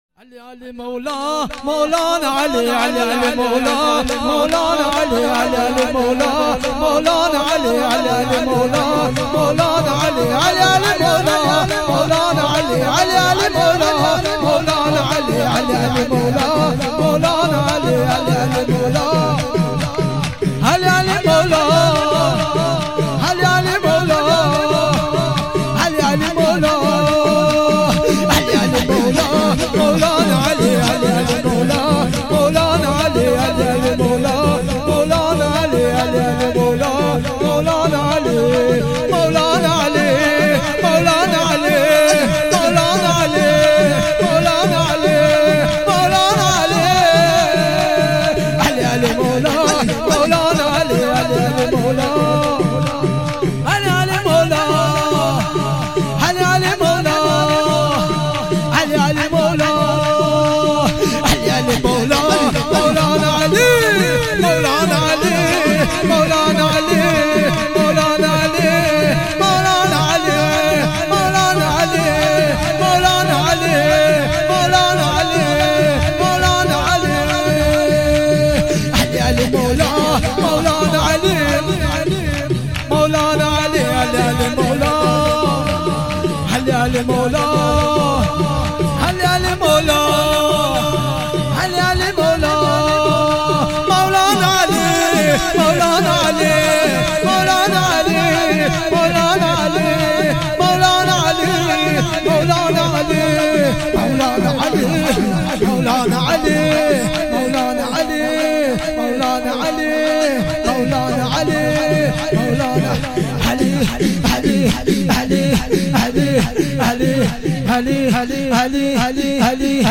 جشن دهه کرامت 97